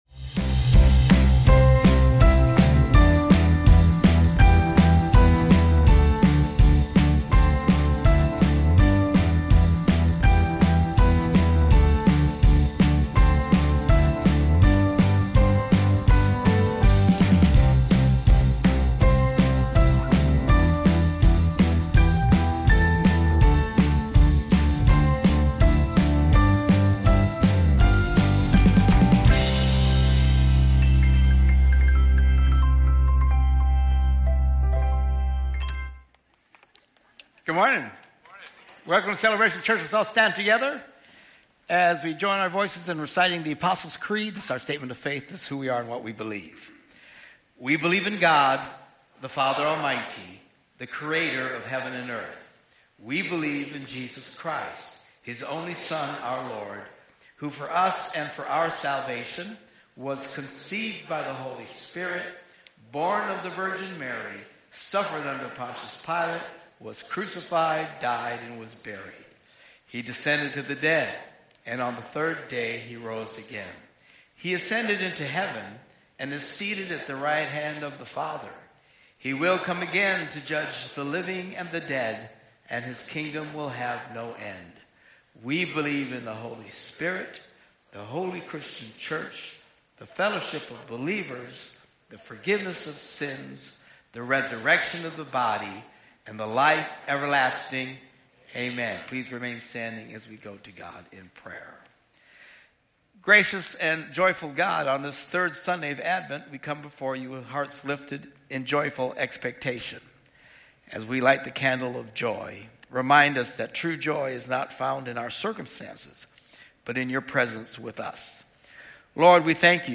This is the weekly message from Celebration Church in Green Bay, Wisconsin.